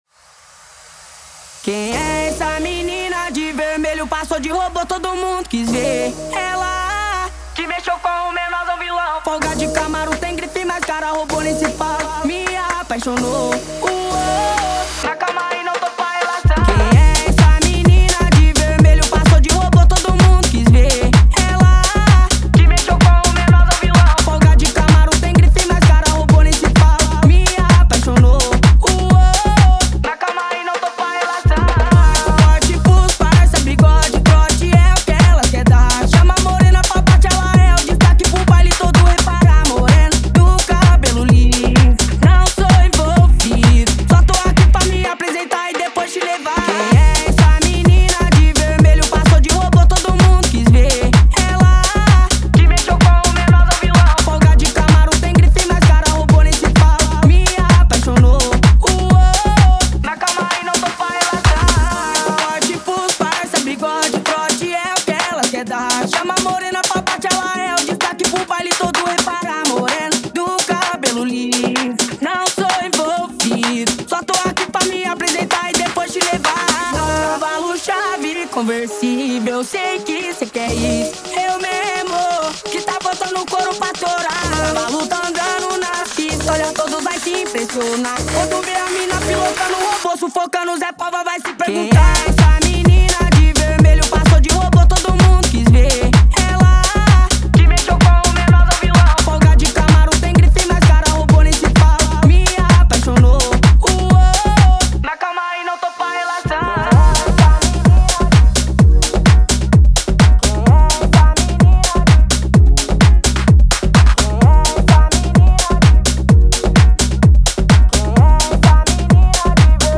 BPM: 128